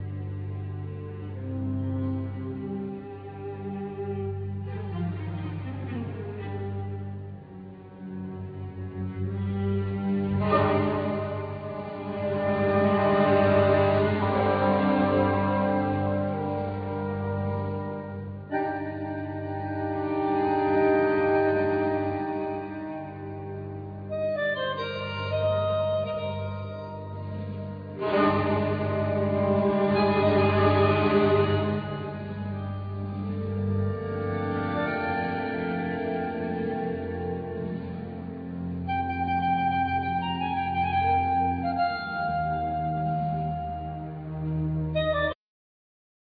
Piano,Synthe Solo
Lyricon
Bandoneon
Ney
Drums
Bass
Percussion,Vocal